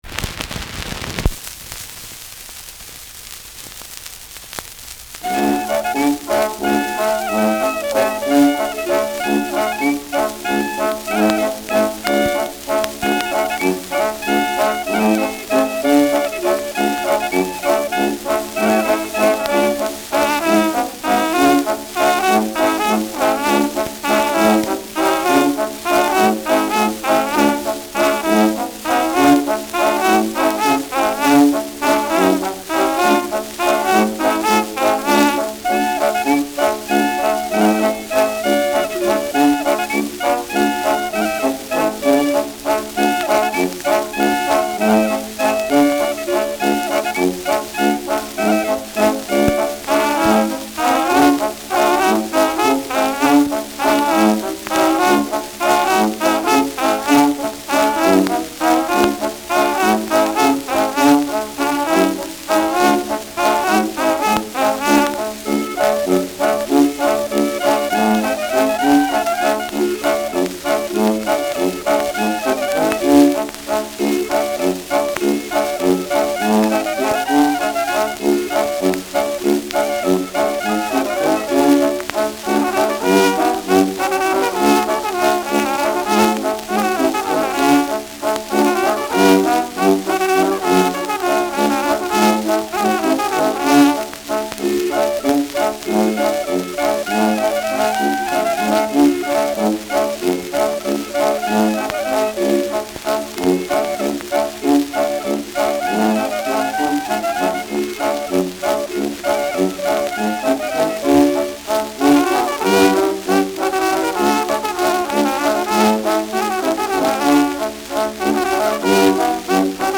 Schellackplatte
Abgespielt : Vereinzelt leichtes Knacken
Ein Tonartwechsel erfolgt nicht. Die Praxis zwei Zwiefache zu kombinieren ist heute noch im Hersbrucker und Sulzbacher Birgland verbreitet.